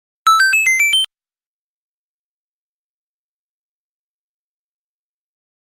mario 1up sound effects
mario-1up